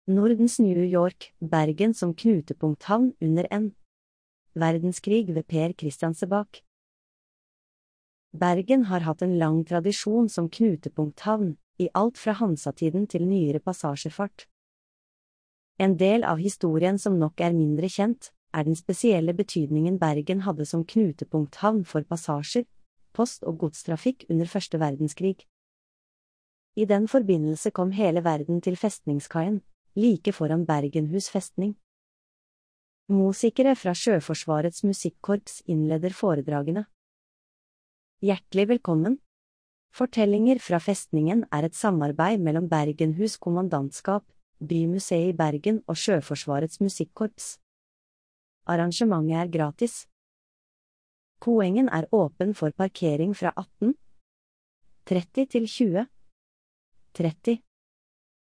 Fortellinger fra Festningen er historiske foredrag, samarbeid mellom Bergenhus Kommandantskap, Bymuseet i Bergen og Sjøforsvarets musikkorps.